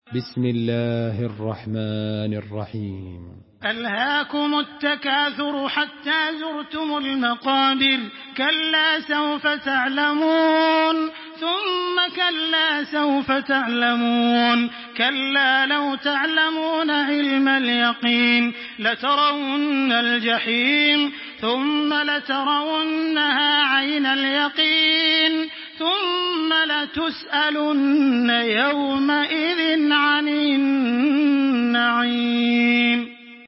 تحميل سورة التكاثر بصوت تراويح الحرم المكي 1426
مرتل